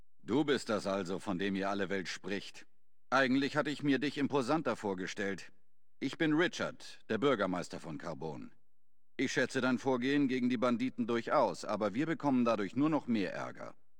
FOBOS-Dialog-Richard-001.ogg